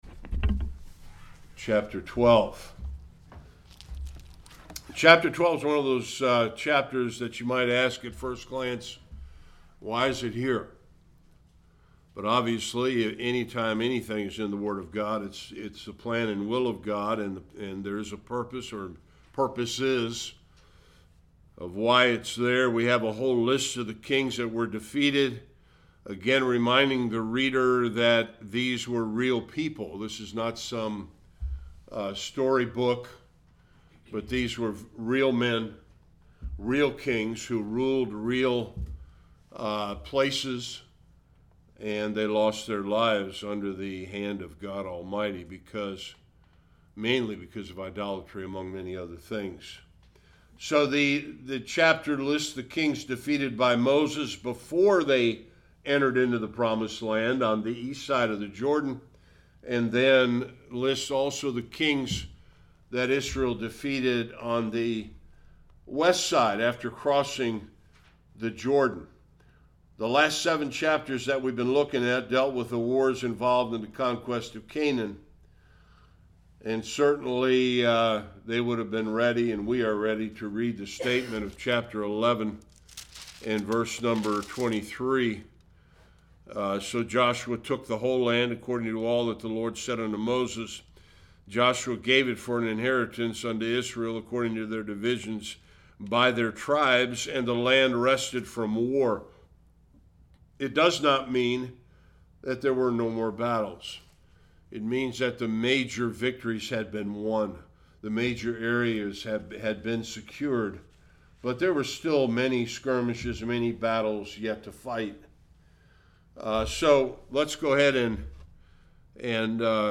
1-18 Service Type: Sunday School The main enemies have been defeated